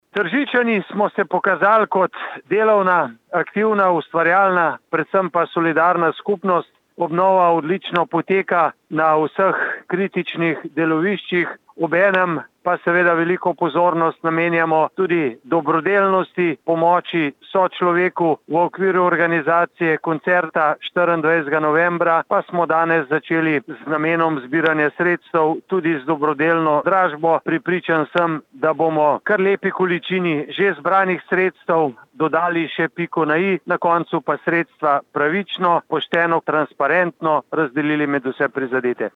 izjava_mag.borutsajoviczupanobcinetrzic_dobrodelnadrazba.mp3 (945kB)